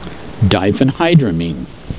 Pronunciation
(dye fen HYE dra meen)